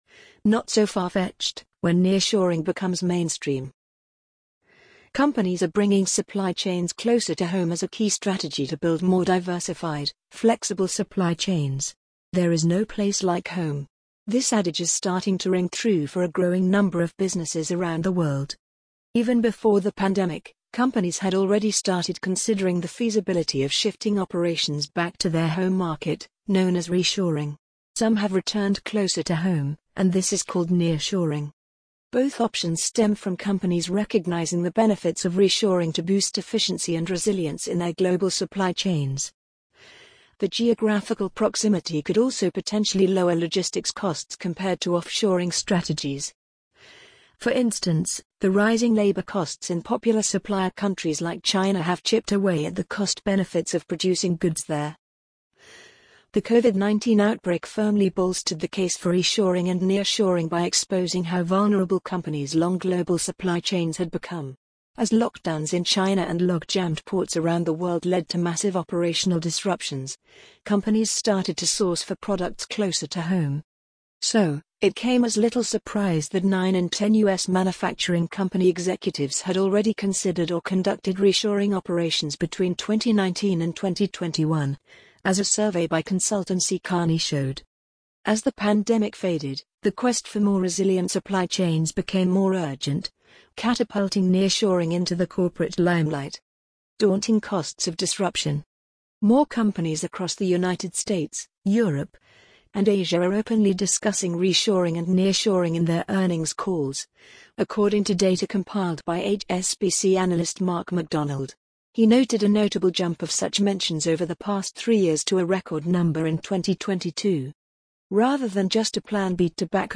amazon_polly_45440.mp3